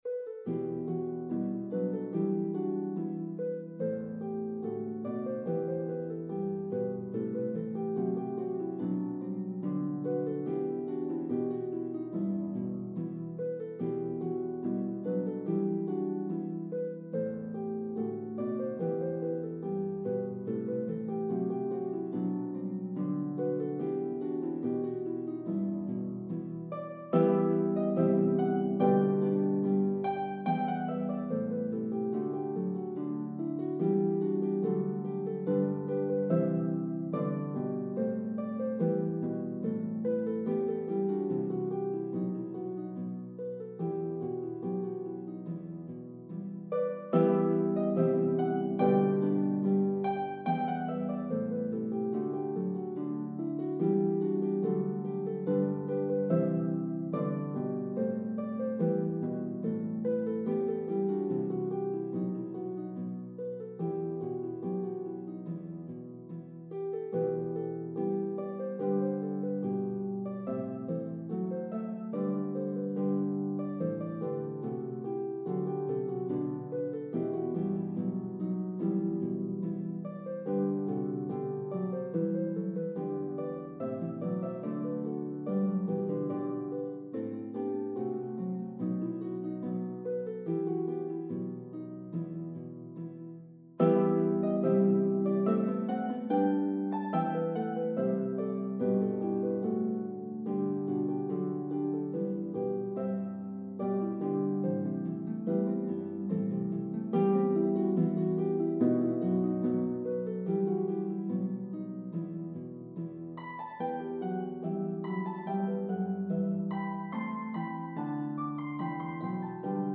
The melody is divided evenly between parts.